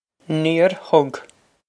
Pronunciation for how to say
Neer hug
This is an approximate phonetic pronunciation of the phrase.